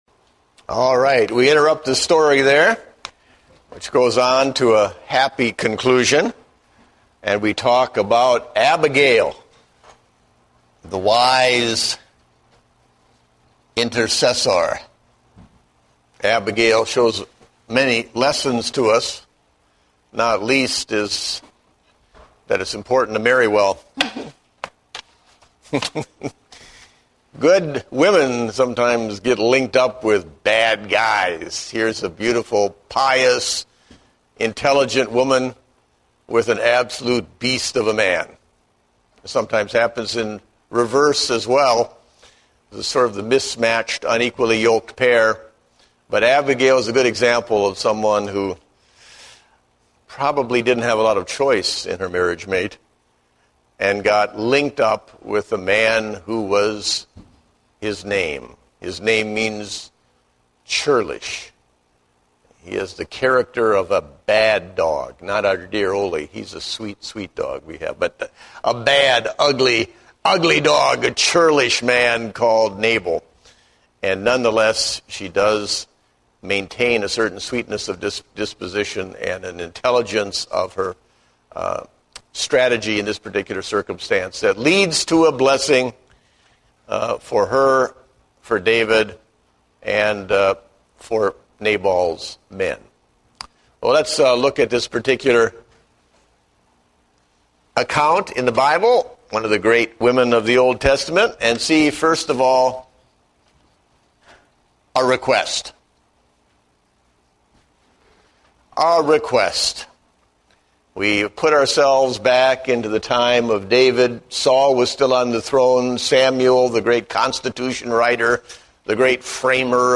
Date: August 8, 2010 (Adult Sunday School)